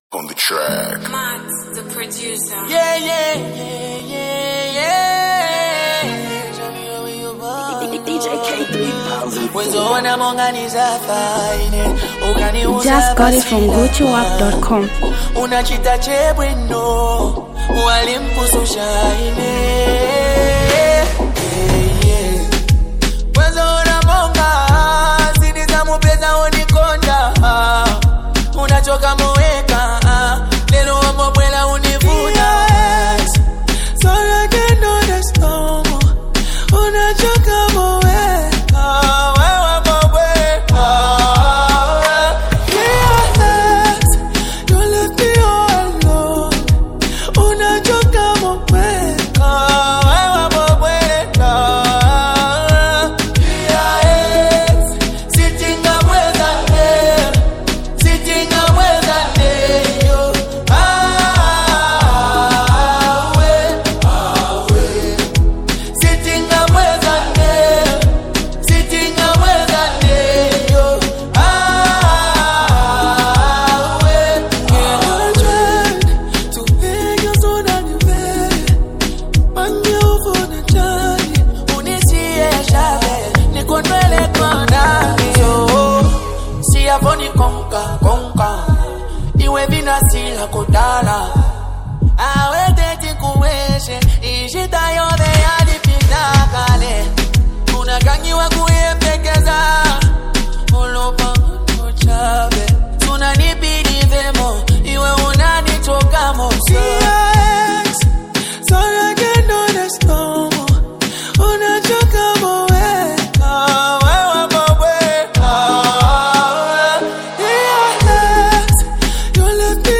Zambian Mp3 Music
buzzing lovable rhyme